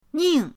ning4.mp3